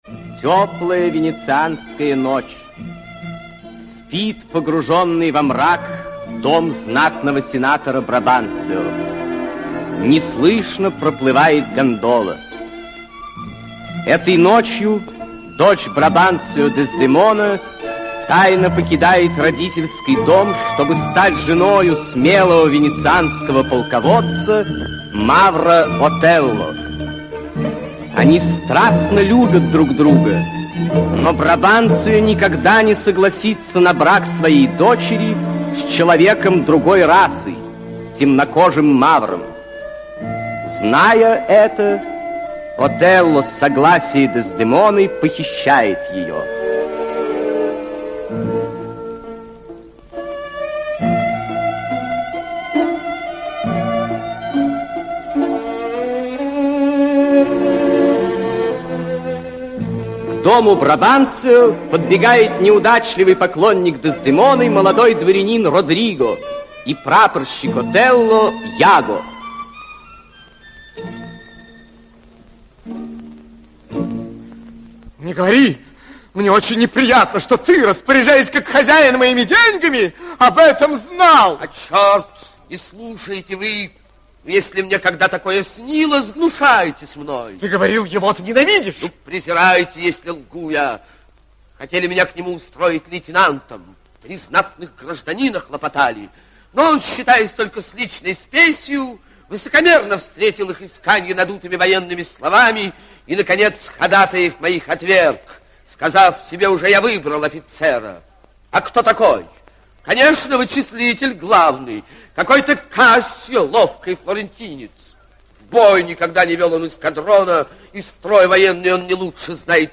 Аудиокнига Отелло (спектакль) | Библиотека аудиокниг
Aудиокнига Отелло (спектакль) Автор Уильям Шекспир Читает аудиокнигу А. Остужев.